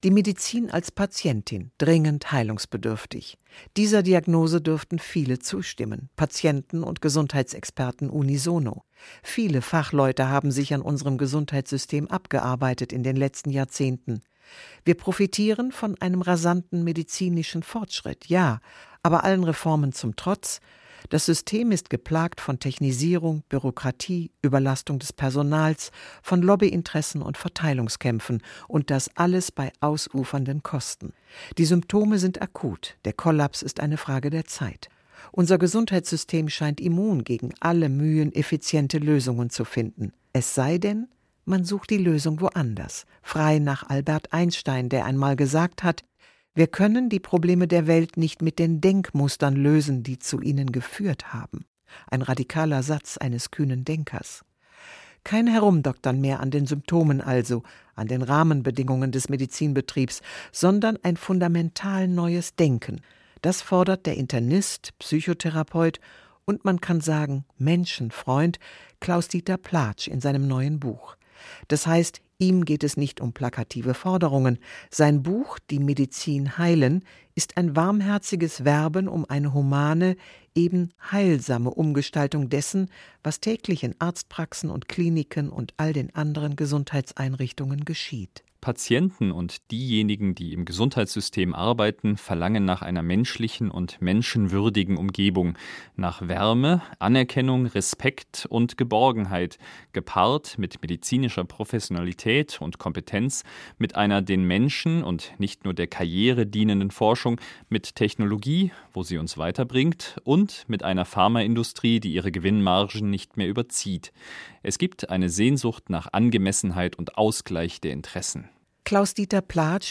Besprechung des Buches „Die Medizin heilen“ im Bayerischen Rundfunk vom 9. Oktober 2014 (fünf Minuten).